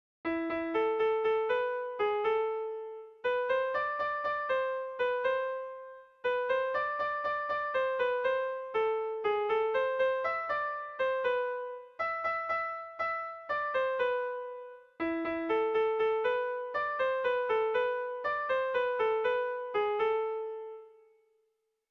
Irrizkoa
ABDEAF